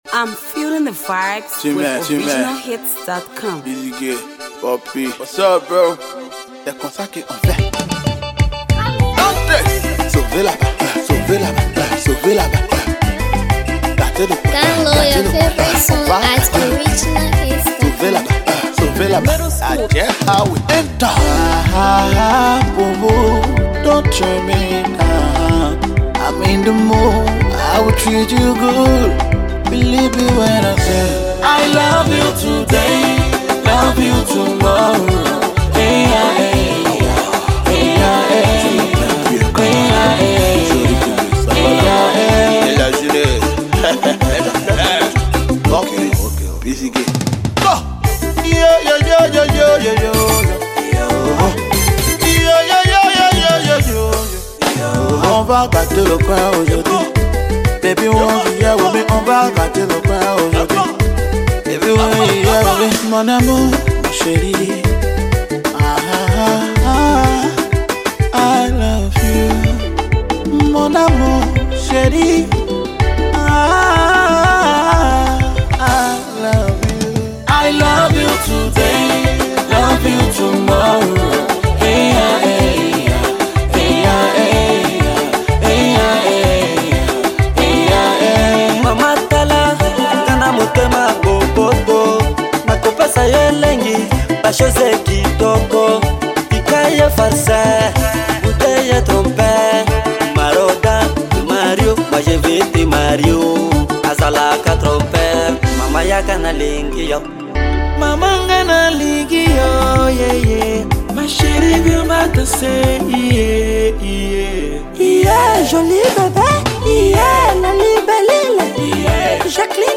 a dubious vibing tune